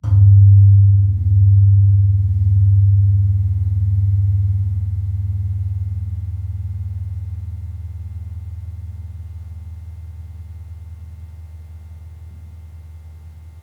Gamelan Sound Bank
Gong-G1-f.wav